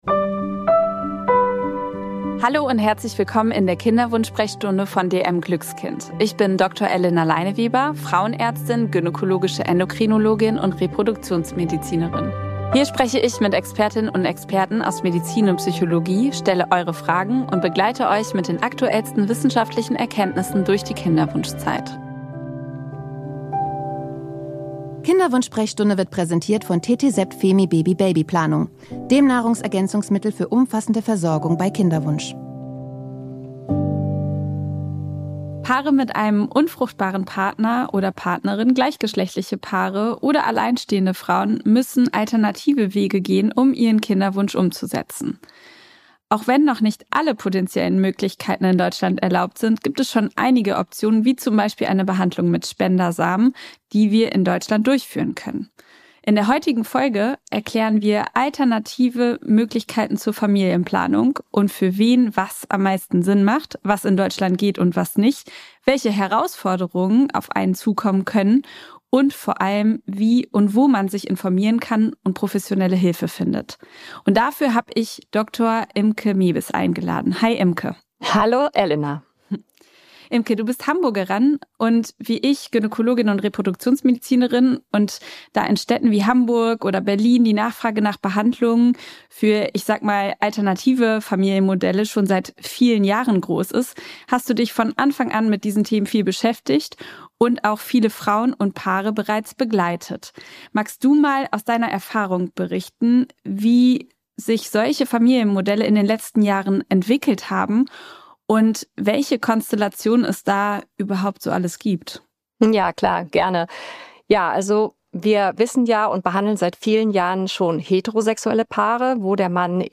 von Solomutterschaft bis zu alternativen Familienmodellen ~ KINDERWUNSCH SPRECHSTUNDE - Der Expertentalk